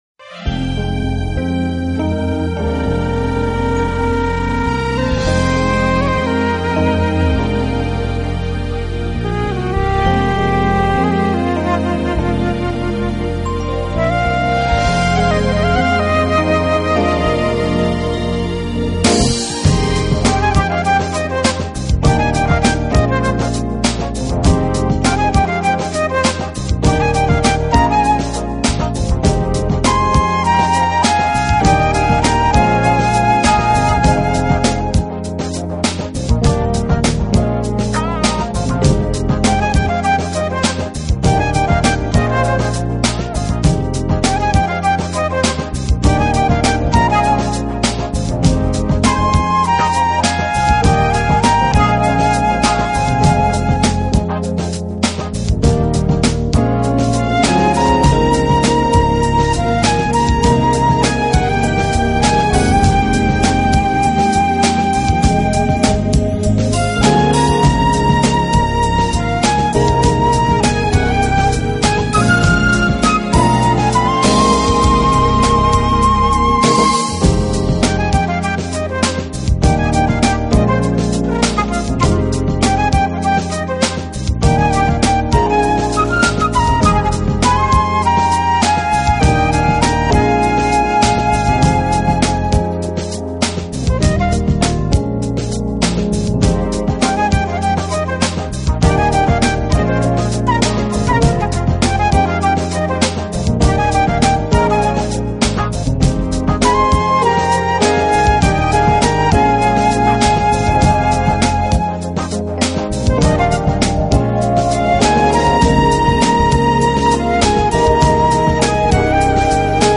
【爵士长笛】
音乐类型: Smooth Jazz
Urban的东西理解十分透彻，Soul的配器和Acid Jazz的节奏，流畅的instrumental flute，